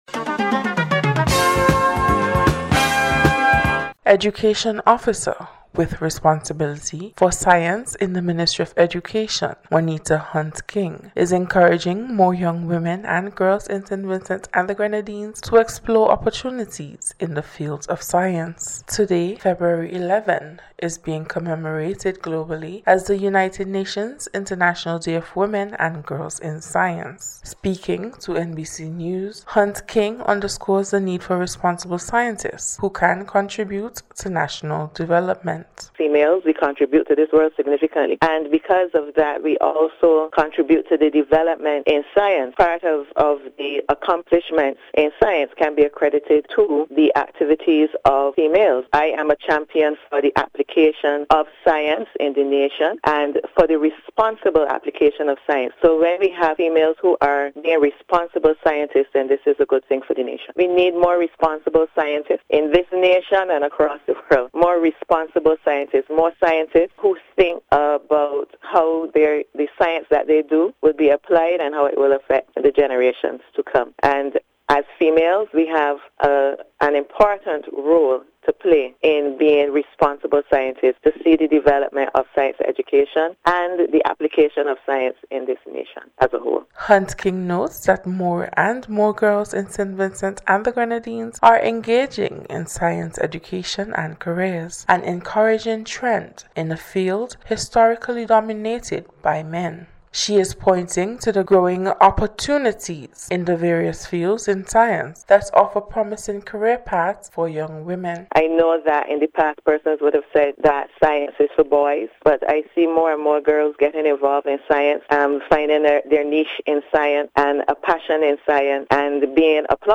NBC’s Special Report- Tuesday 11th February,2025